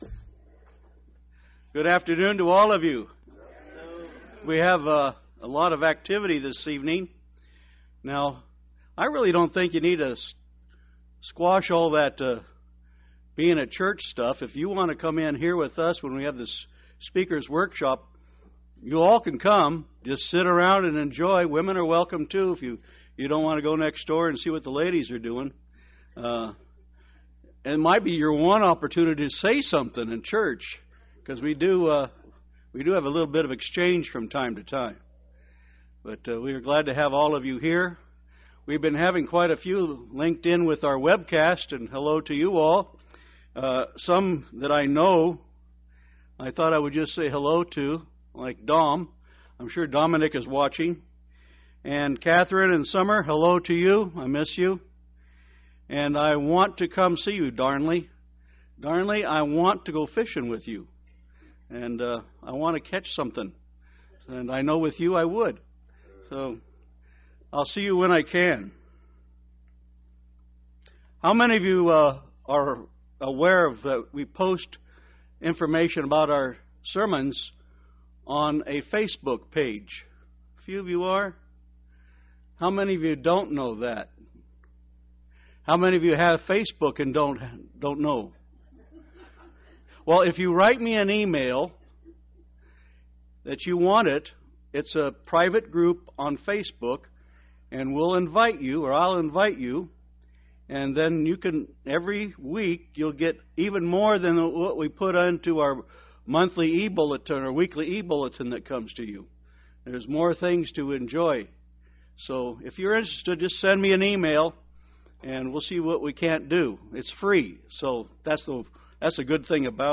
Given in Tampa, FL St. Petersburg, FL